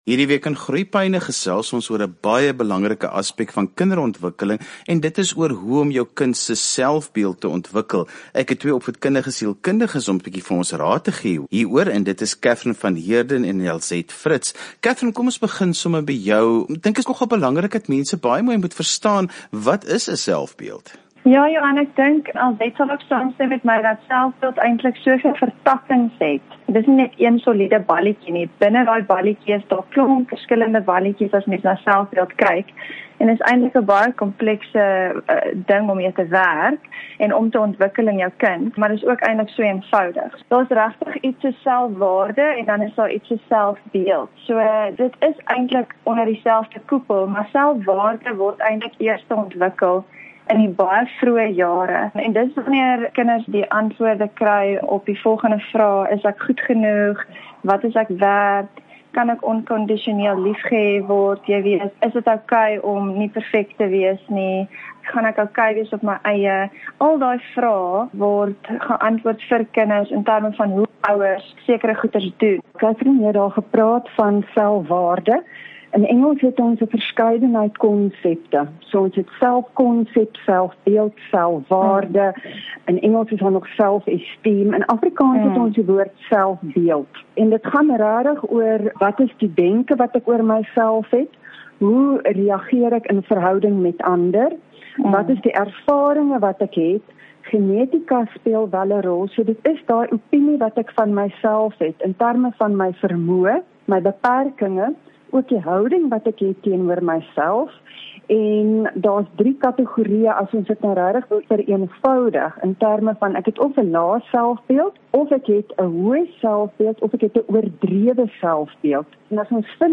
praat met opvoedkundige sielkundiges